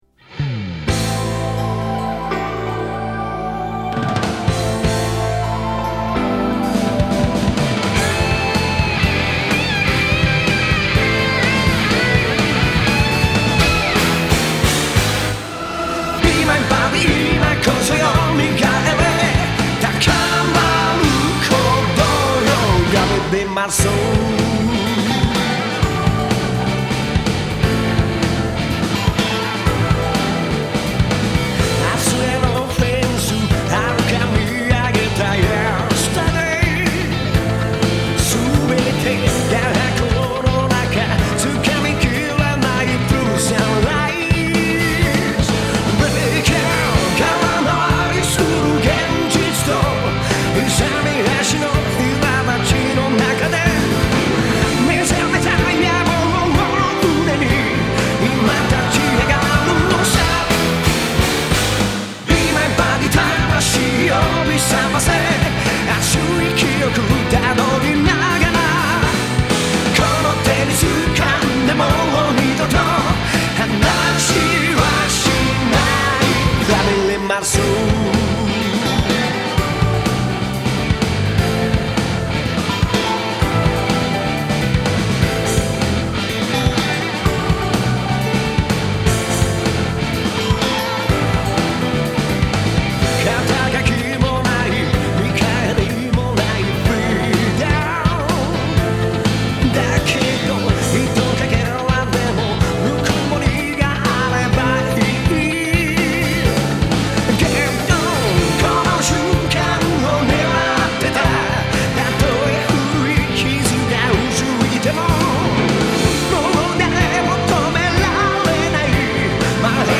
보컬의 목소리가 정말도 터프하고 멋지군요.